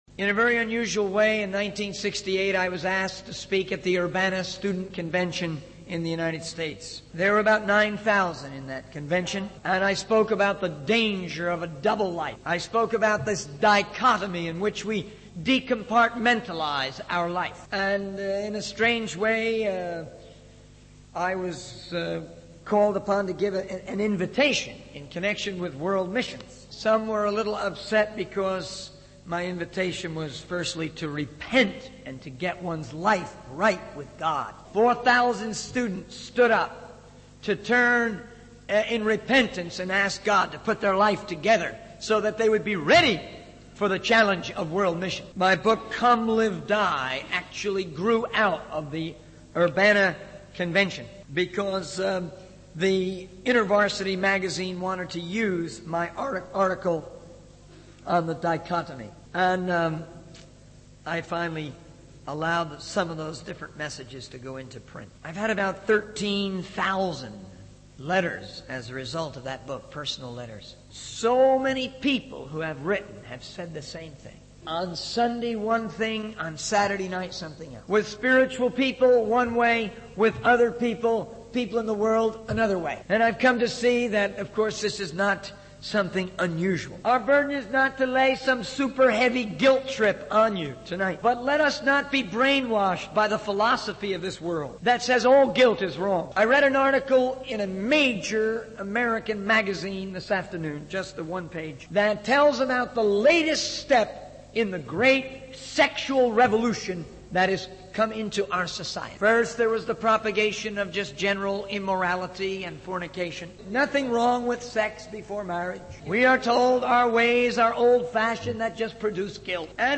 In this sermon, the preacher discusses the importance of hating sin and embracing goodness according to the teachings of the Bible.